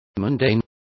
Complete with pronunciation of the translation of mundane.